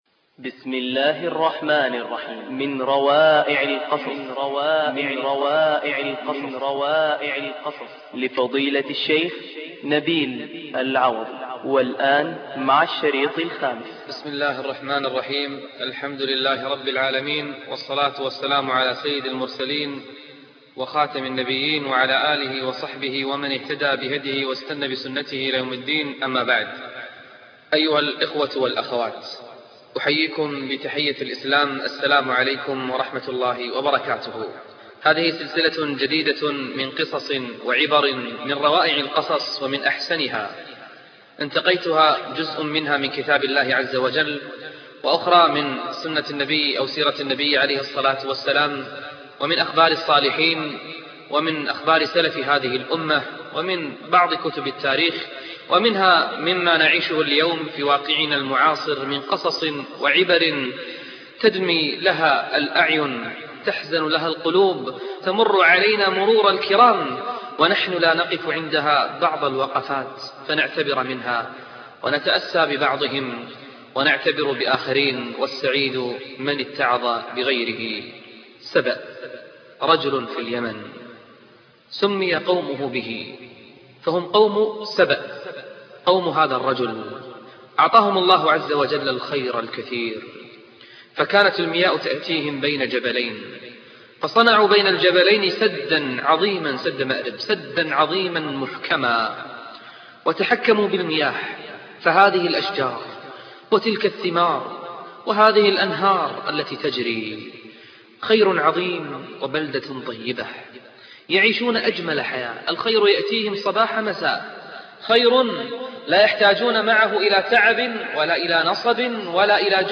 الدرس الخامس - فضيلة الشيخ نبيل العوضي